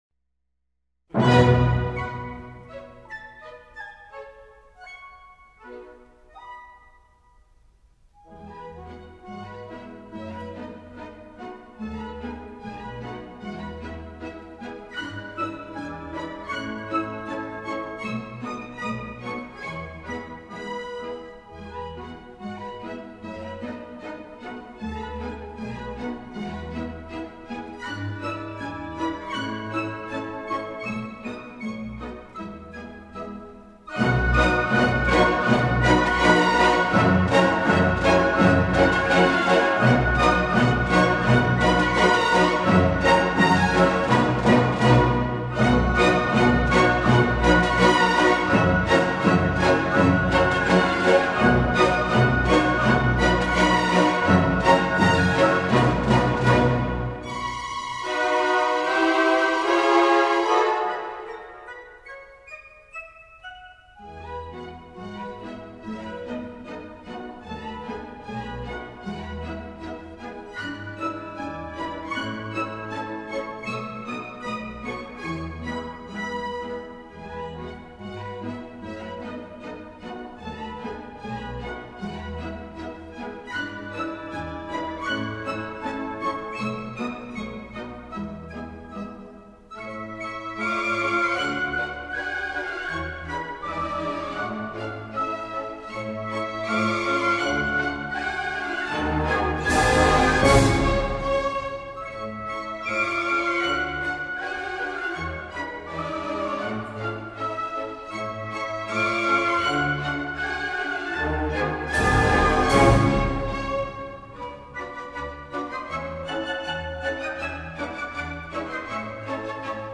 语言：纯音乐
此集音乐主题都是具有舞曲性、缓抒情的咏唱性
和田园诗般的歌唱性，表现出音乐独特的诗意，
具有少见的磅礴气势，但不乏温馨迷人的舞曲旋律
和沉静的呤唱，一张一弛的乐句强化了对比性的特点，